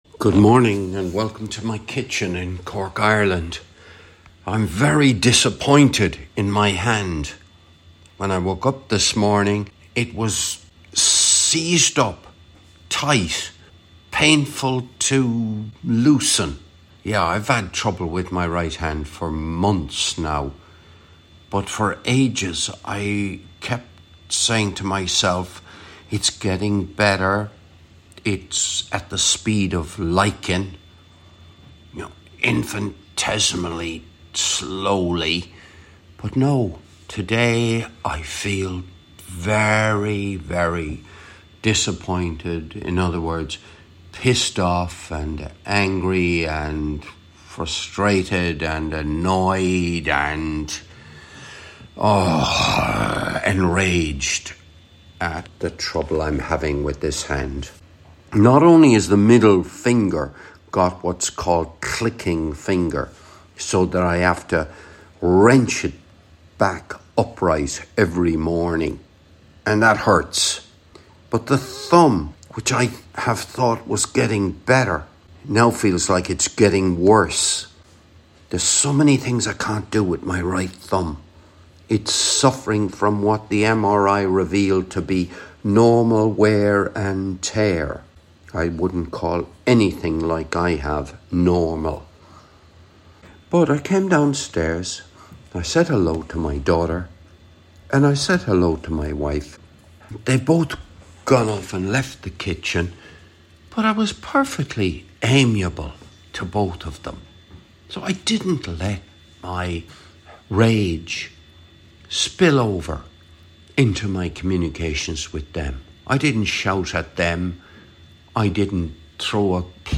This was recorded in the kitchen on Sunday morning 15th December 2024.